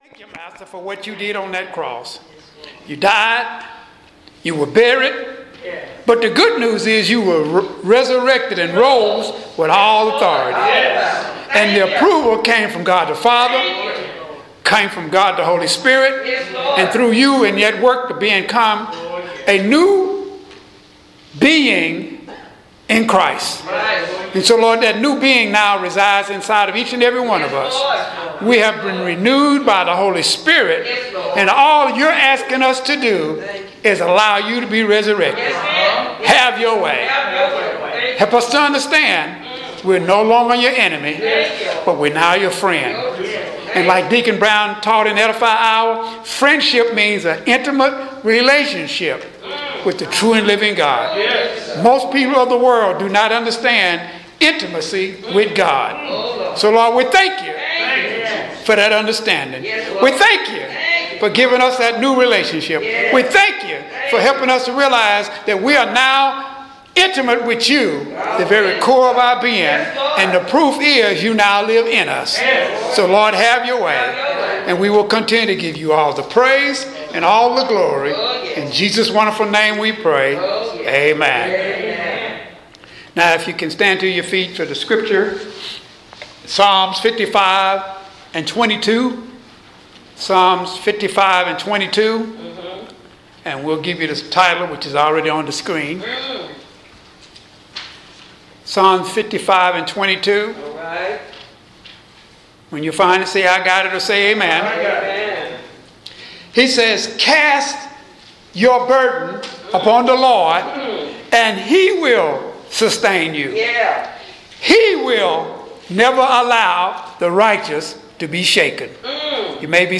Sermon Scripture Psalms 55:22 (NKJV) 22 Cast your burden on the Lord , And He shall sustain you; He shall never permit the righteous to be moved.